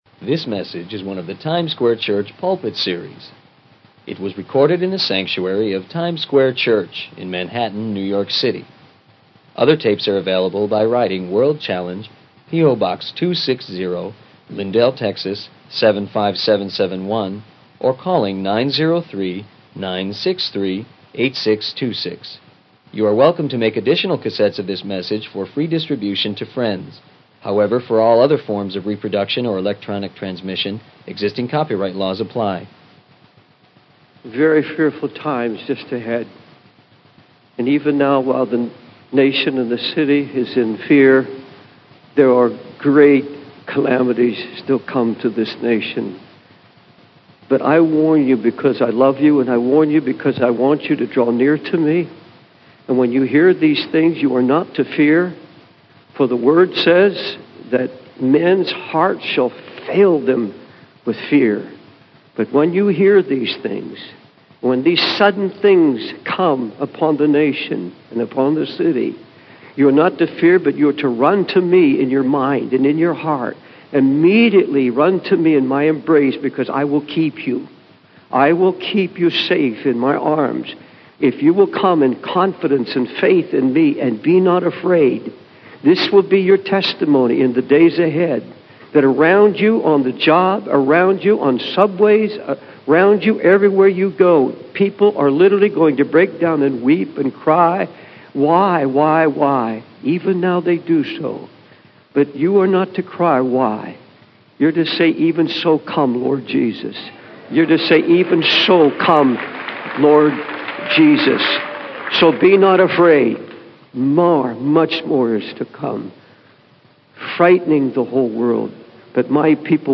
In this sermon, the preacher warns of fearful times ahead and the calamities that will come upon the nation.